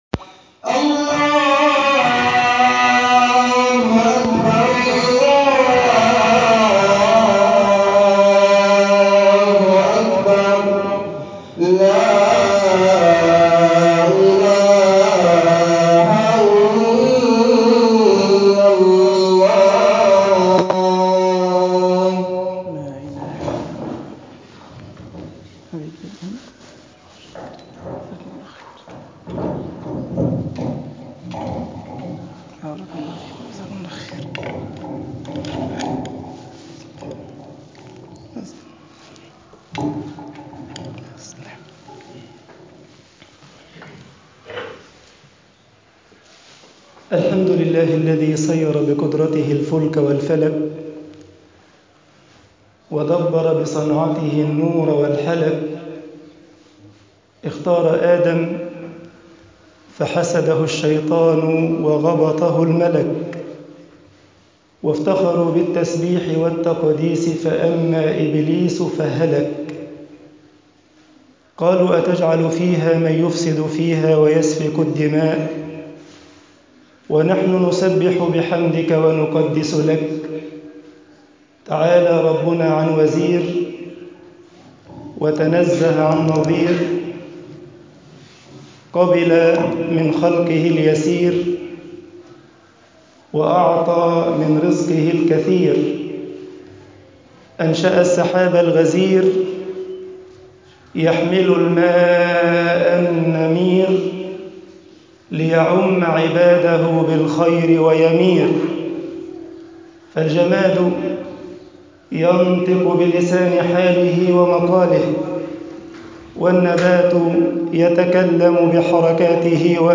الإنسان بين معركتين - خطبة الجمعه
خطب الجمعة والعيد